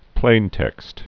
(plāntĕkst)